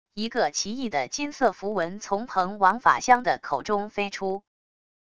一个奇异的金色符文从鹏王法相的口中飞出wav音频生成系统WAV Audio Player